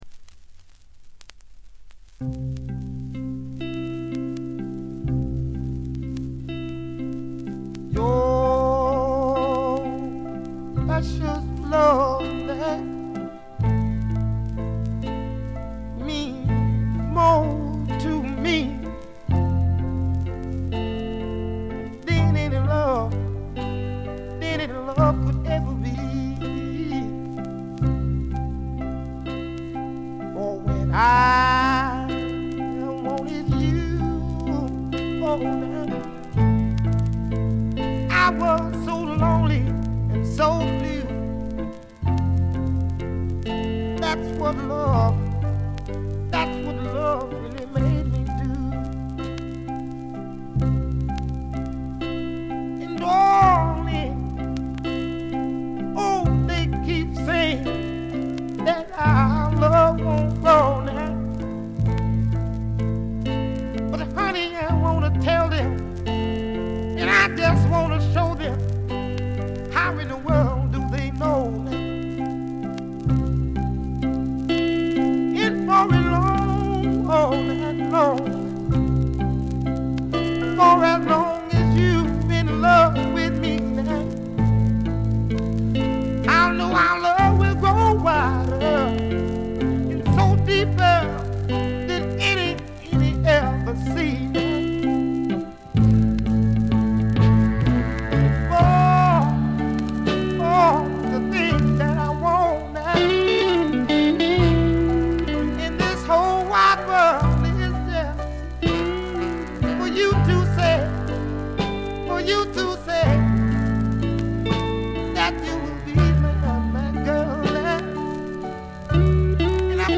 バックグラウンドノイズ（A2序盤が特に目立つ）、チリプチ。
モノラル盤。
試聴曲は現品からの取り込み音源です。